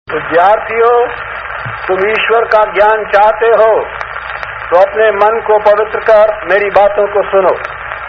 MALAVIYAJI'S VOICE